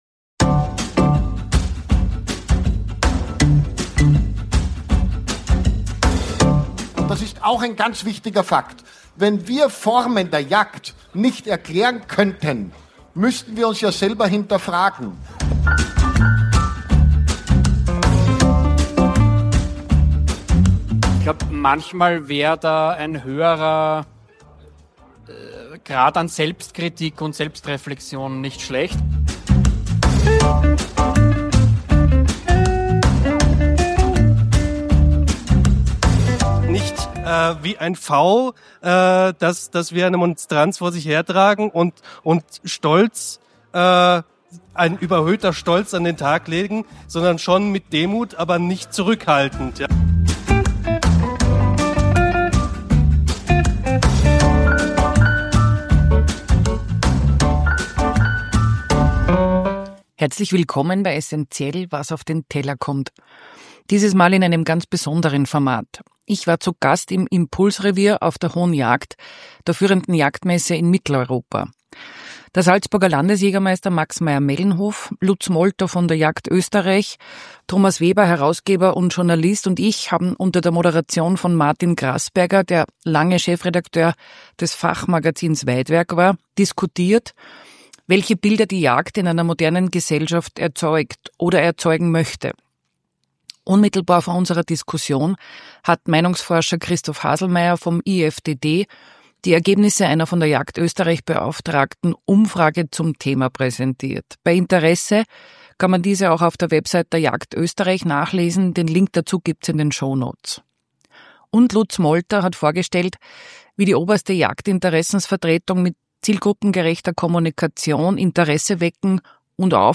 Eine durchaus kontroversielle Diskussion zur Rolle der Jäger:innen, zu Bedeutung und Verfügbarkeit von Wildbret und zur Frage, ob das Bild der Jagd tatsächlich so kritisch ist, wie die Jägerschaft es wahrnimmt.